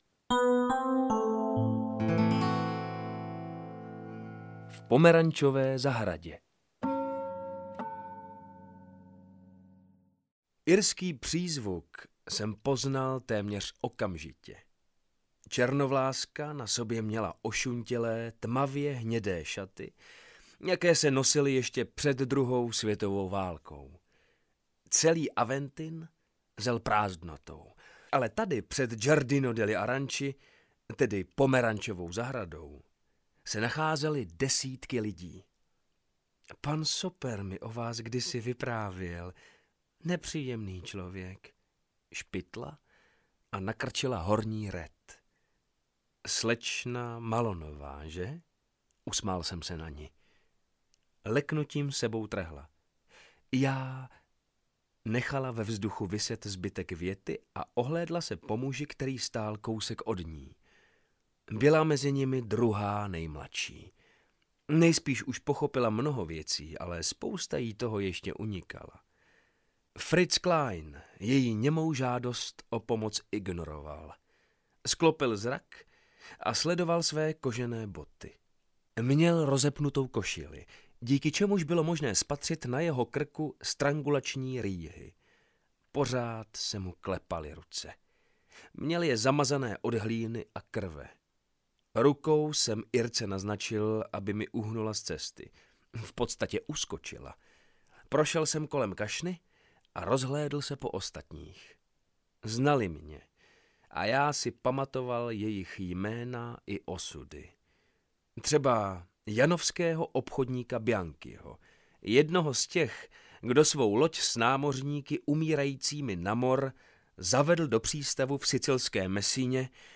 Válkotvůrci audiokniha
Ukázka z knihy